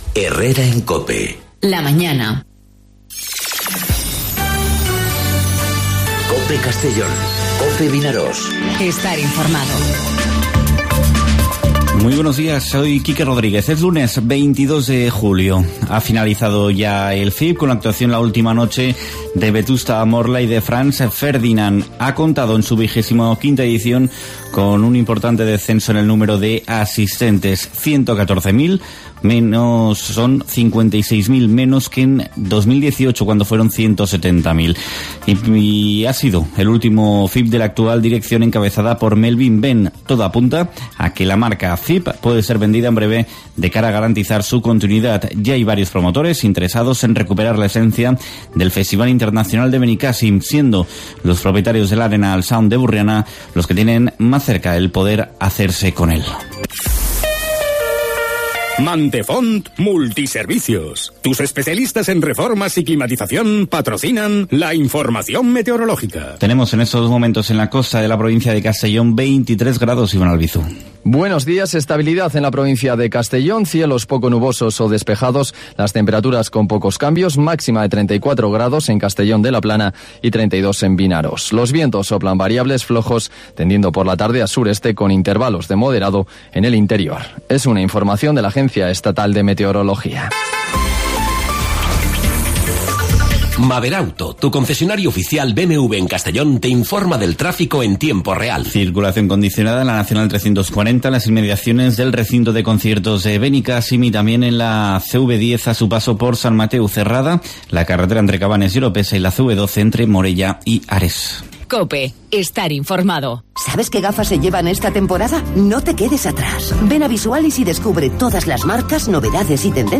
Informativo 'Herrera en COPE' Castellón (22/07/2019)